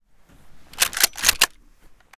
/ gamedata / sounds / weapons / mosin / bolt_new.ogg 70 KiB (Stored with Git LFS) Raw History
bolt_new.ogg